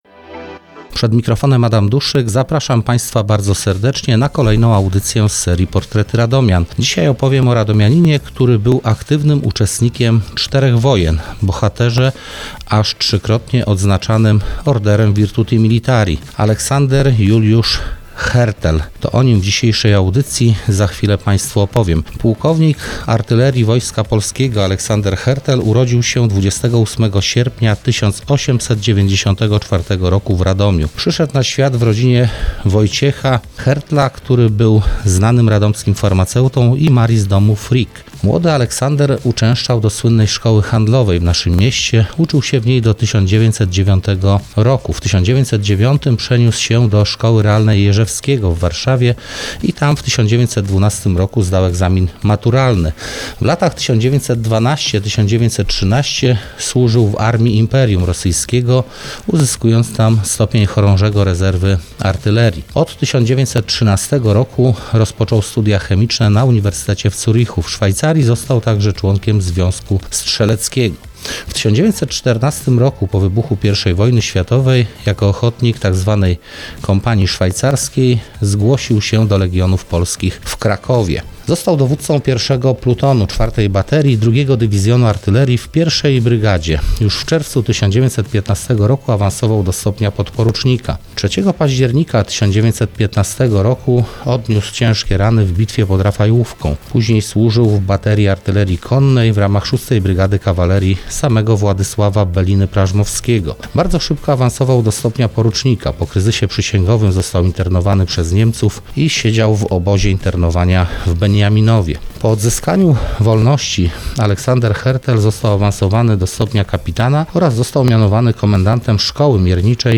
Dzisiejszym bohaterem cyklu „Portrety Radomian” jest pułkownik artylerii Wojska Polskiego, uczestnik czterech wojen, trzykrotnie odznaczony Orderem Virtuti Militari płk Aleksander Hertel. Jego sylwetkę przedstawił historyk